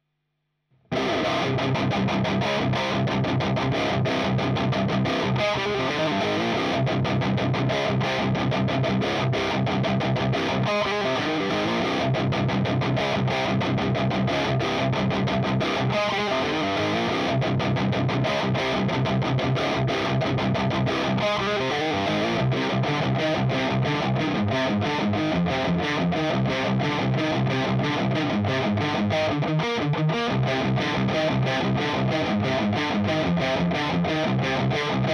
Voici 4 sample de chaque canal en Vintage/Modern.
Le Red mode Vintage :
Le Master est compensé sur les 2 canaux car le Red sonne moins fort le Orange.